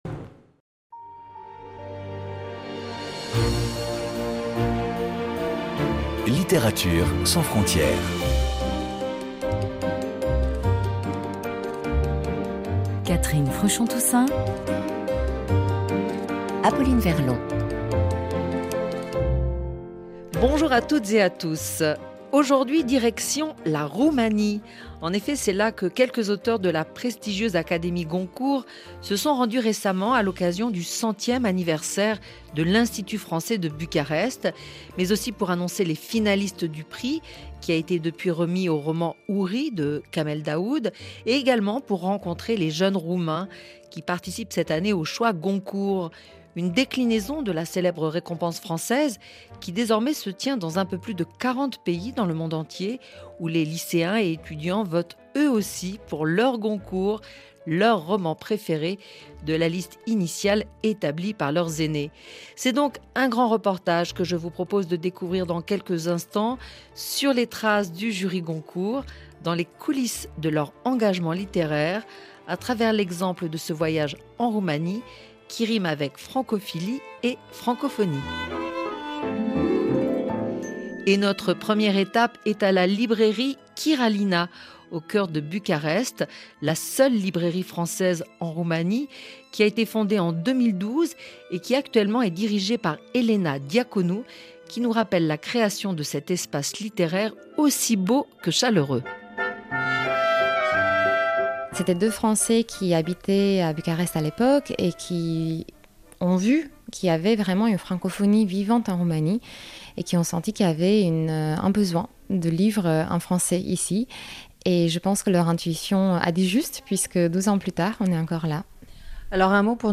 1 Reportage en Roumanie: la francophilie littéraire avec le jury Goncourt 29:00